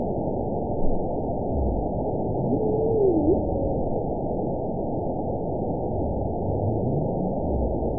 event 922483 date 01/02/25 time 04:44:34 GMT (5 months, 2 weeks ago) score 9.03 location TSS-AB06 detected by nrw target species NRW annotations +NRW Spectrogram: Frequency (kHz) vs. Time (s) audio not available .wav